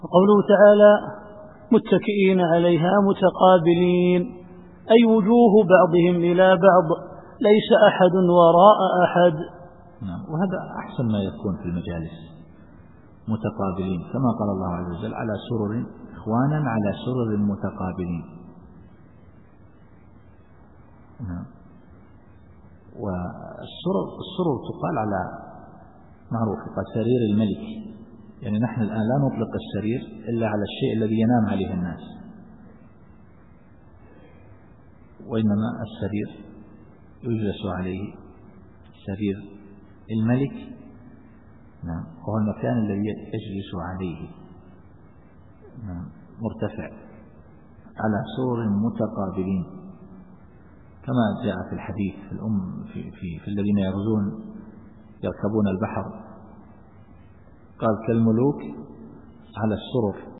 التفسير الصوتي [الواقعة / 16]